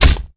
wrenchflesh01.wav